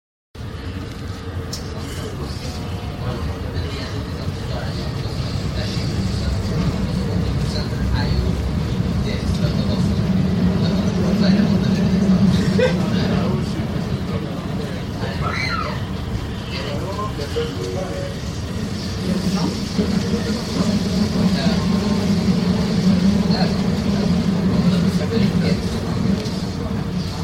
火车到达 格伦兰Tbane站
描述：在Grønlandtbane站从左到右记录火车到达。设备：Roland R26，内部XY和全向麦克风混合为立体声。
标签： 挪威 火车 地铁 奥斯陆
声道立体声